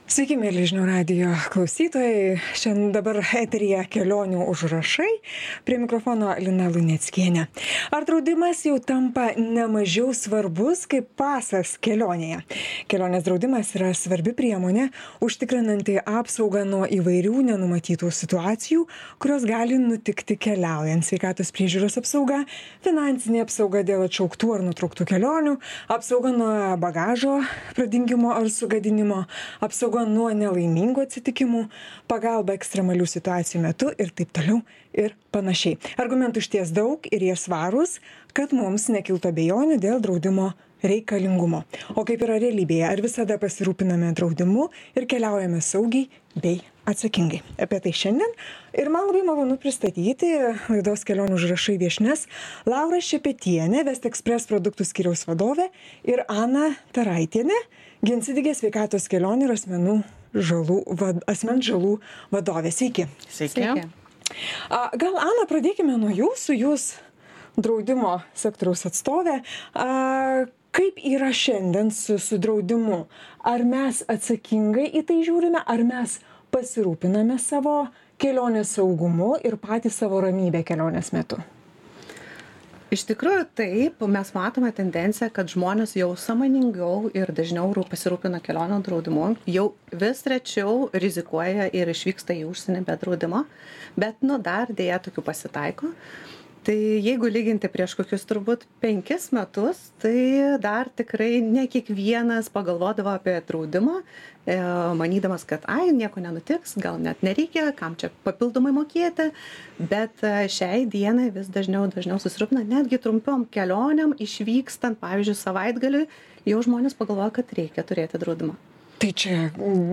Pokalbis